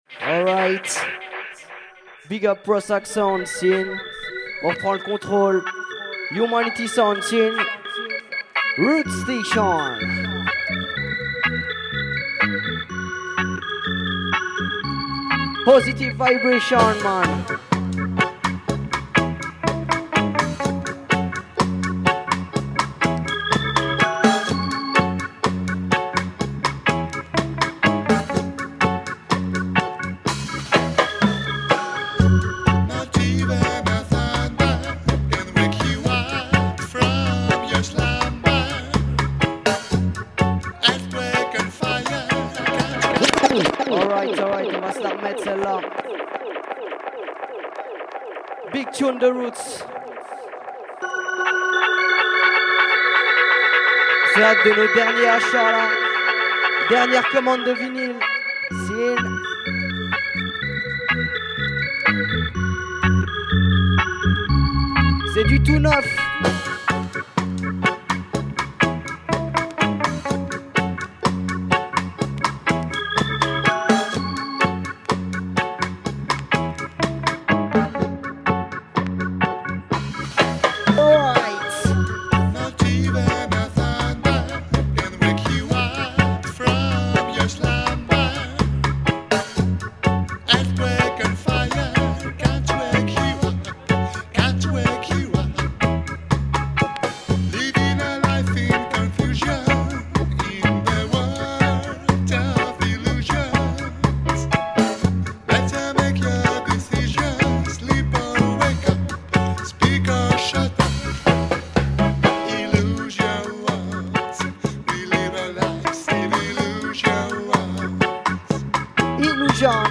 part Roots part Dub and Dub&Dub